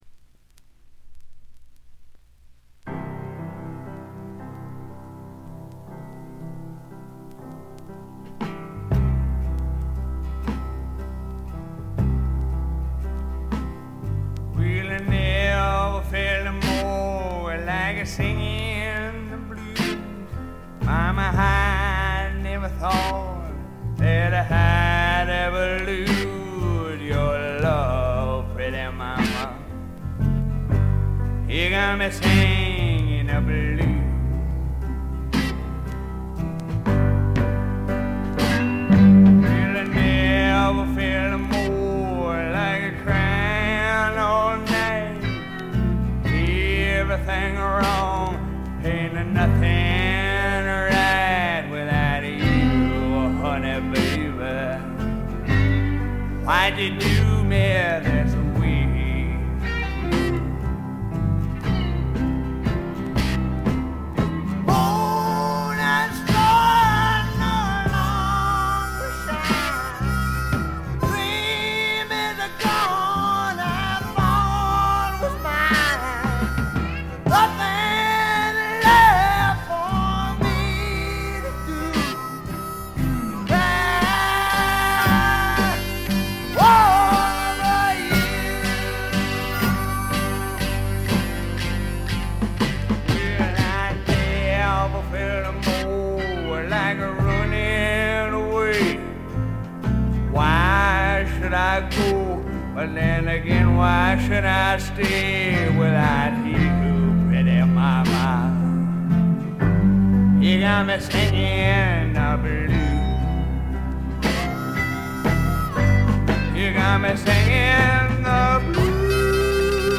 60年代的なポップな味付けを施しながらも、ねばねばなヴォーカルがスワンプど真ん中の直球勝負で決めてくれます。
試聴曲は現品からの取り込み音源です。